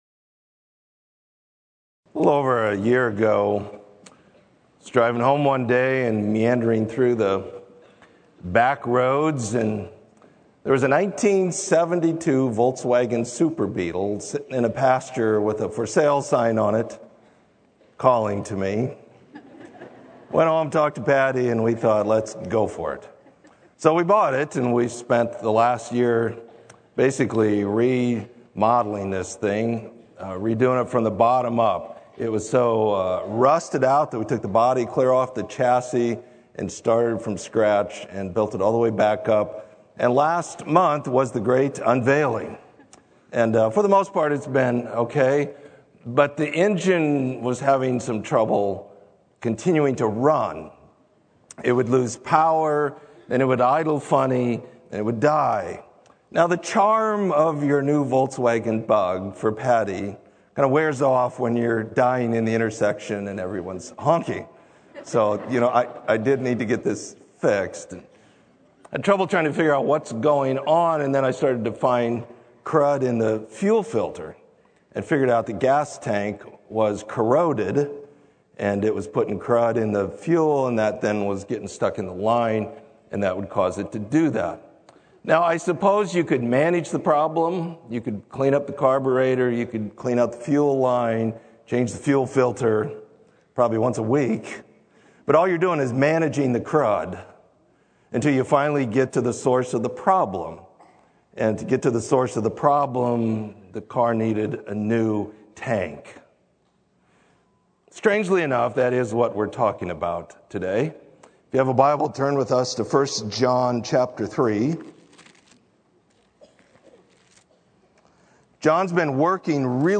Sermon: Believe and Love